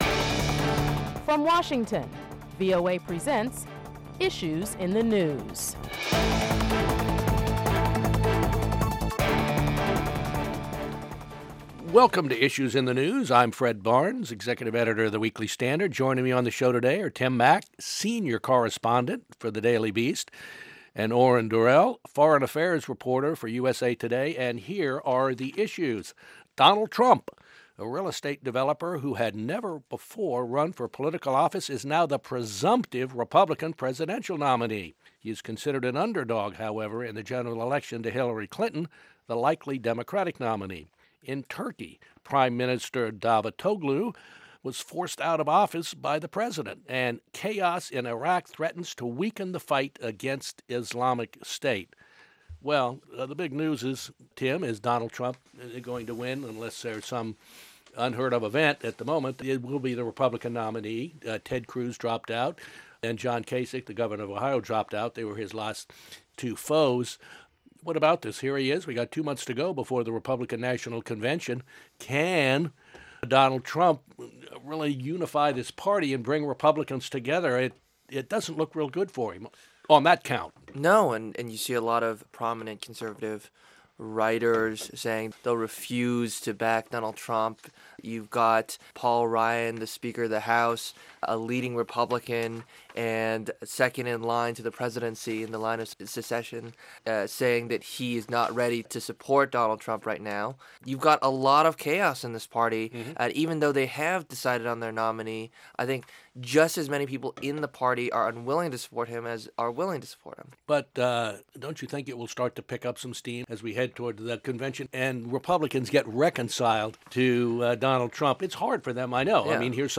Moderator Fred Barnes, Executive Editor of The Weekly Standard